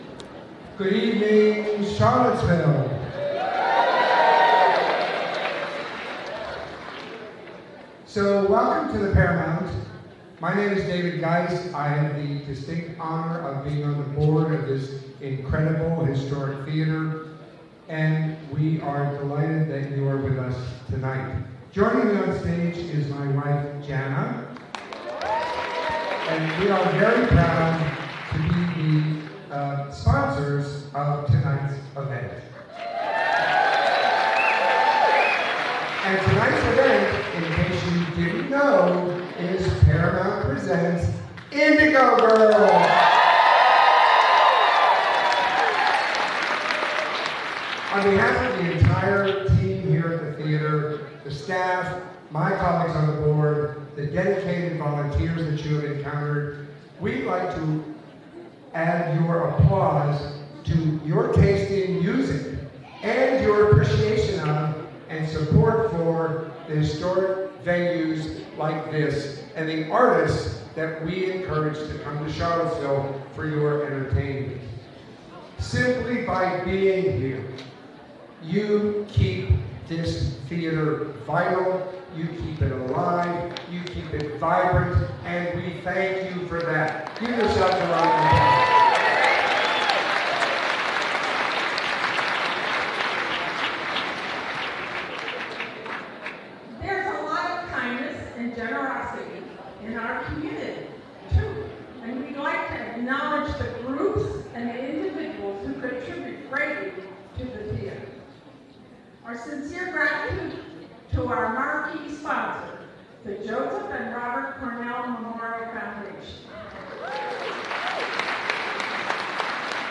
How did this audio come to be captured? lifeblood: bootlegs: 2024-12-15: the paramount theater - charlottesville, virginia (captured from a web stream)